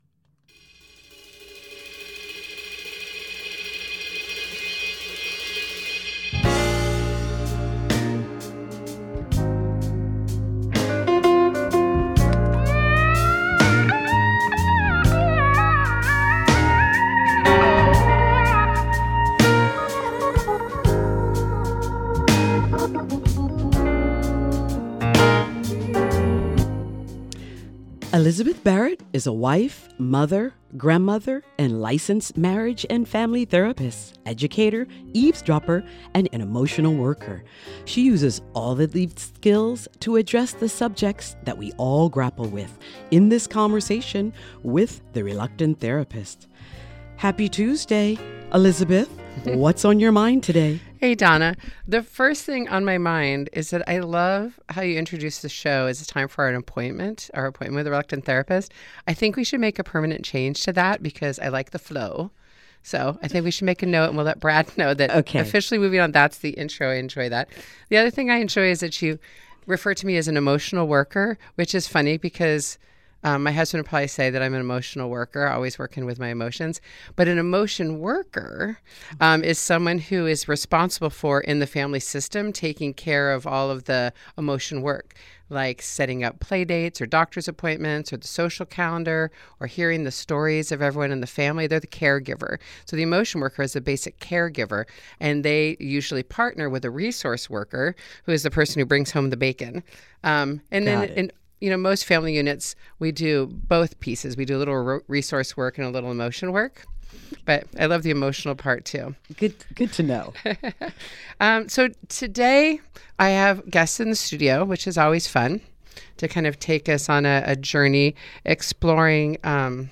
A Conversation with The Reluctant Therapist Flag Ranch – Sanctuary for heart and horses